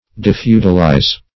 Defeudalize \De*feu"dal*ize\